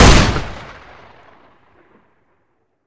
Index of /server/sound/weapons/riflecowboy
wpn_cowrepeat.wav